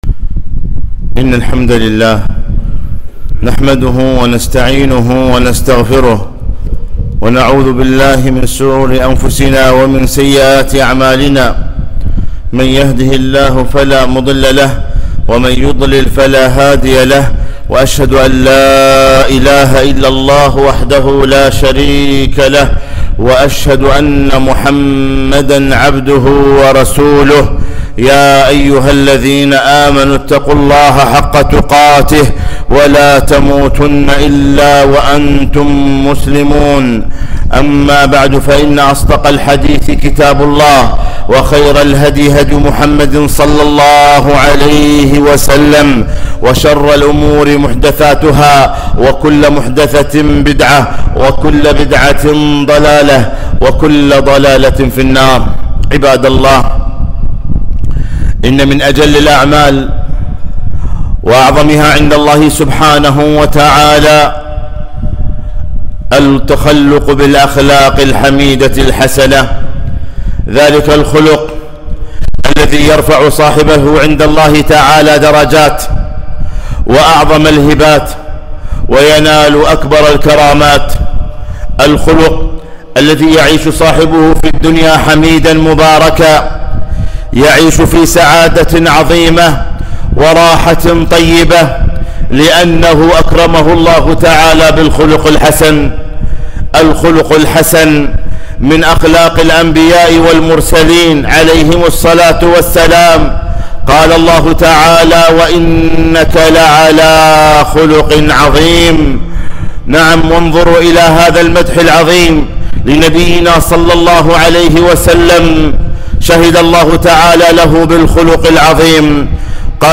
خطبة - وخالق الناس بخلق حسن